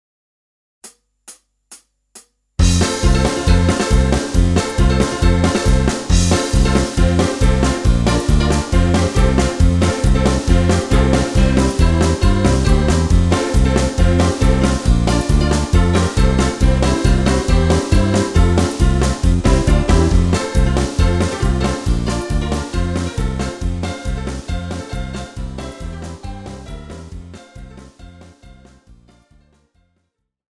Žánr: Pohádková
BPM: 137
Key: F